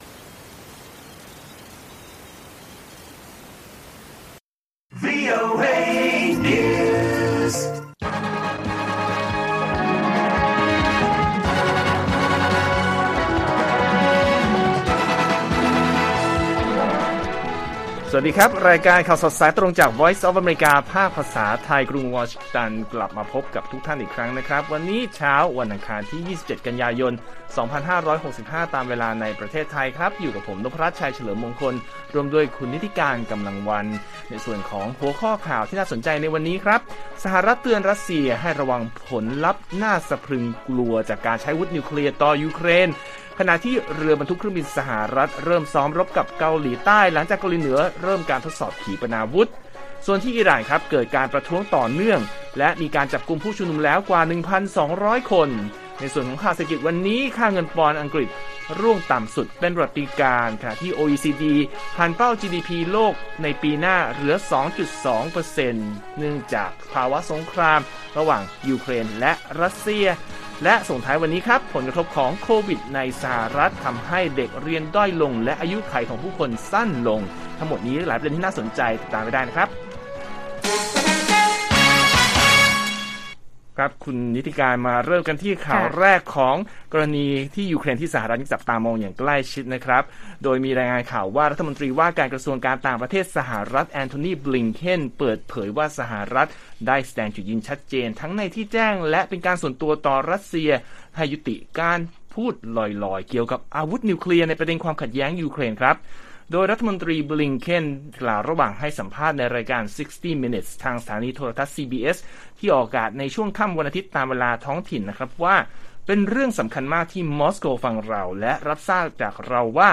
ข่าวสดสายตรงจากวีโอเอ ภาคภาษาไทย 27 ก.ย. 65